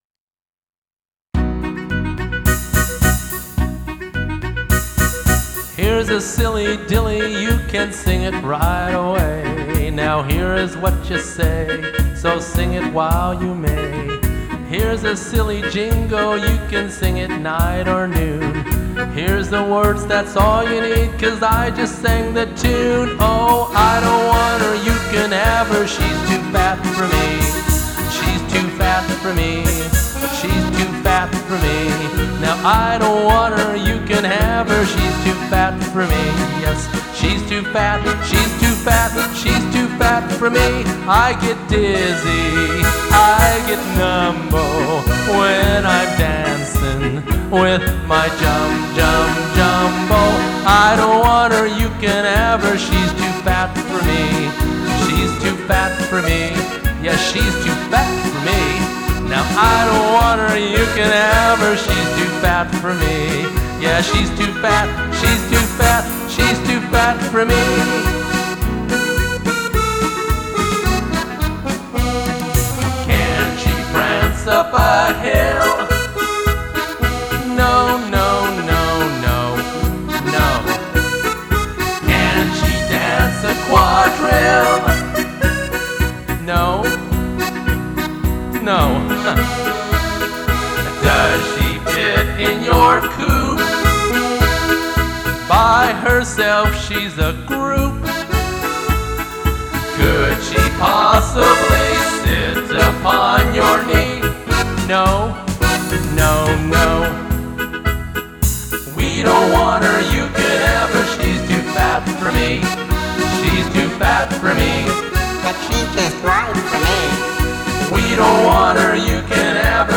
For a vocal harmonizer comparison
have a listen to my old 2007 Tyros2 live rendition of the